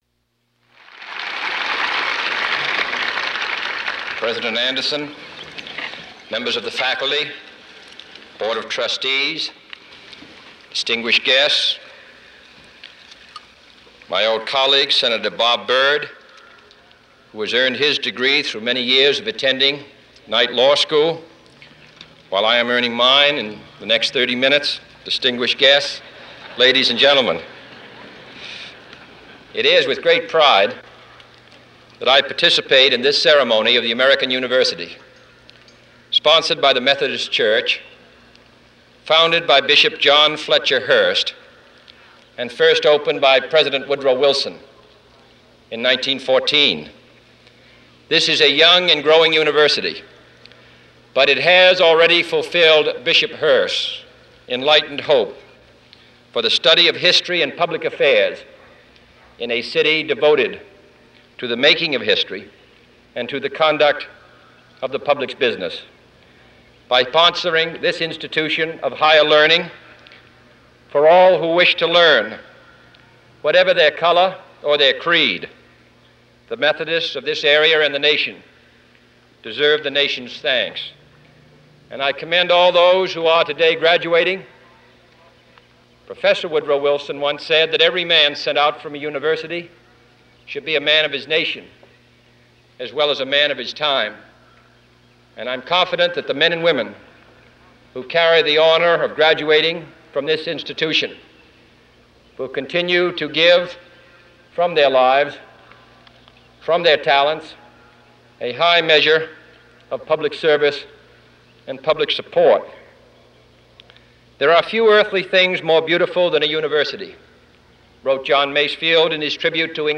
June 10, 1963: American University Commencement